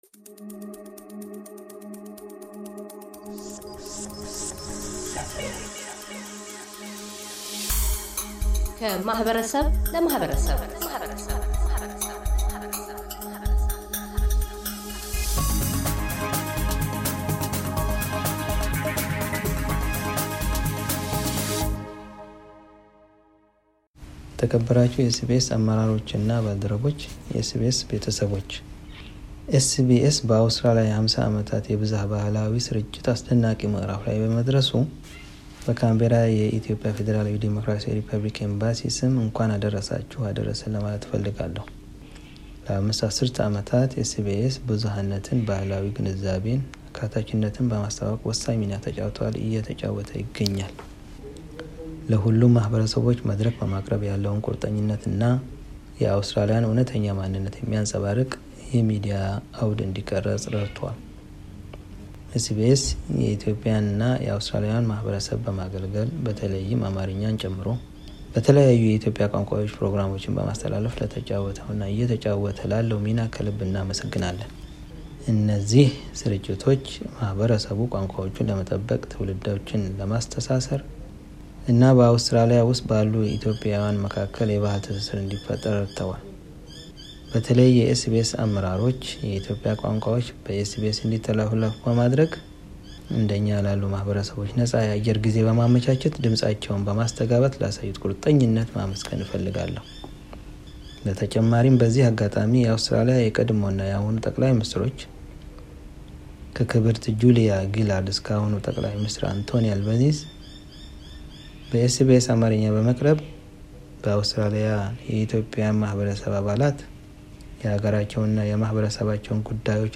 አምባሳደር አንዋር ሙክታር መሐመድ በአውስትራሊያ የኢፌዴሪ ኤምባሲ ምክትል የሚሲዮን መሪ፤ የSBSን 50ኛ ዓመት ክብረ በዓል ምክንያት በማድረግ የእንኳን አደረሳችሁ መልዕክታቸውን ያስተላልፋሉ።